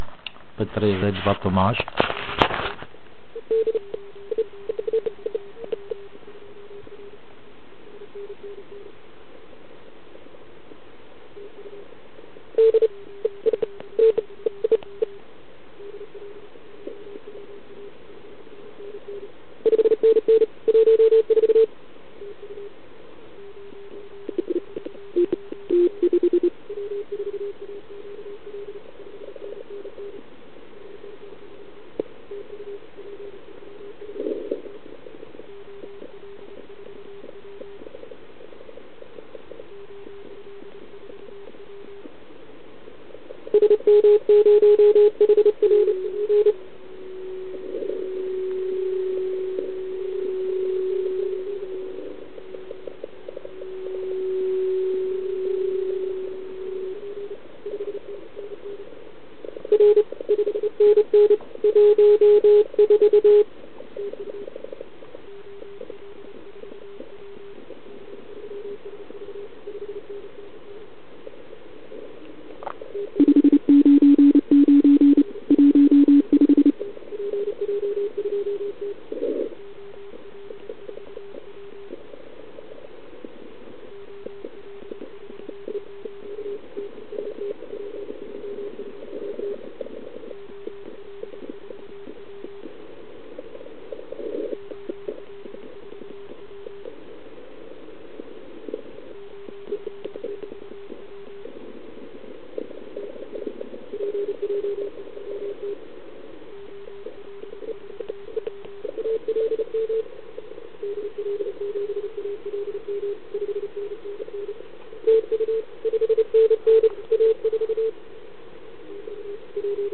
Ale pojďme se zaposlouchat do pár signálků.